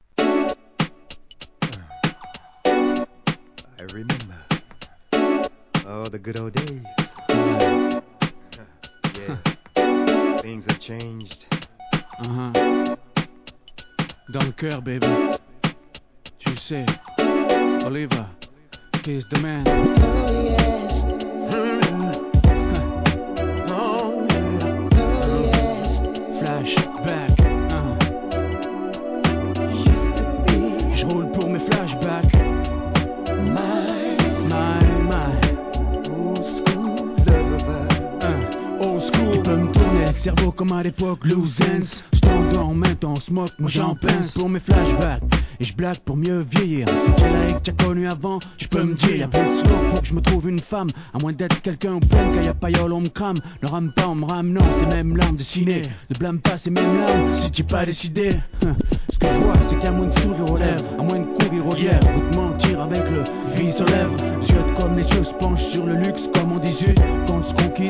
フランス産HIP HOPコンピレーション